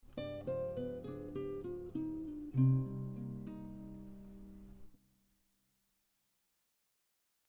Descending line 3
This descending line starts on the 5th chord tone of the G7 b9 and resolves to the root note of C minor 7.